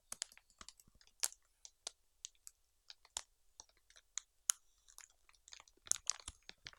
Small Fire Crackle.wav